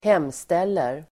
Ladda ner uttalet
Uttal: [²h'em:stel:er]